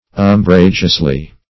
Um*bra"geous*ly, adv.